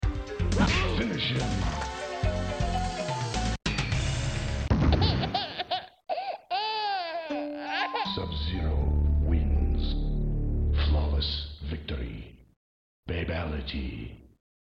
Mortal Kombat II 1993 Arcade sound effects free download